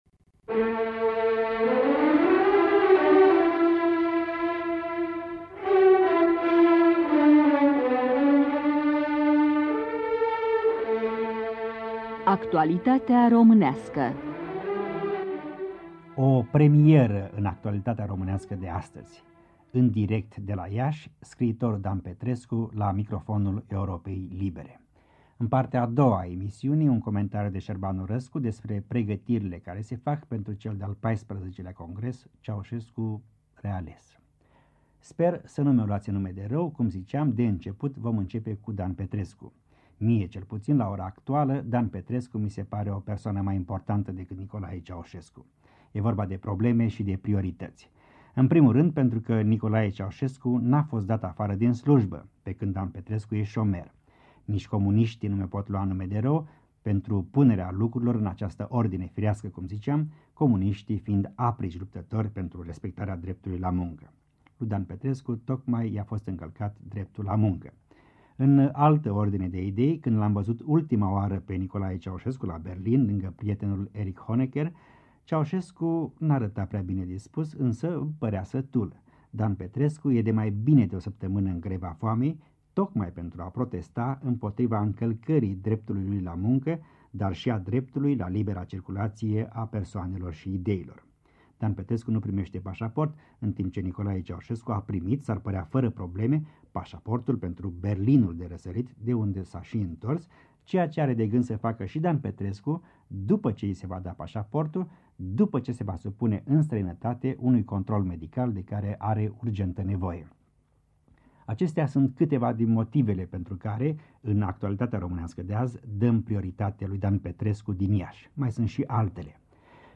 Actualitatea românească: Un interviu telefonic cu disidentul Dan Petrescu la Iași